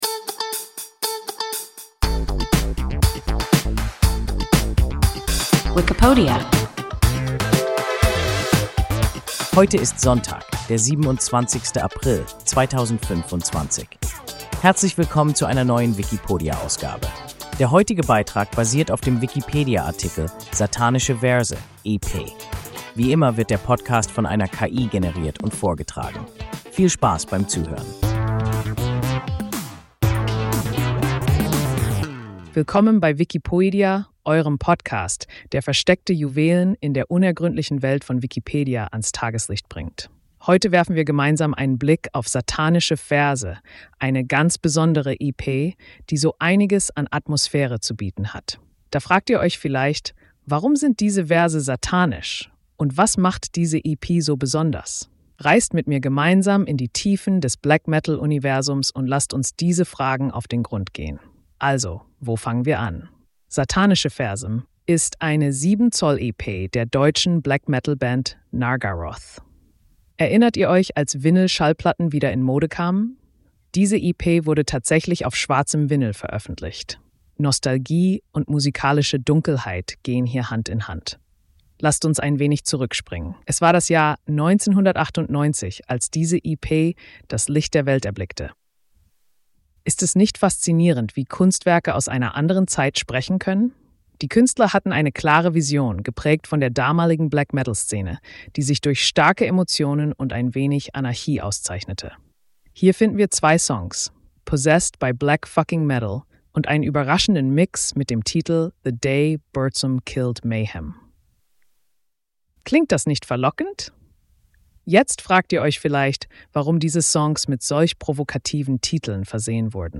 Satanische Verse (EP) – WIKIPODIA – ein KI Podcast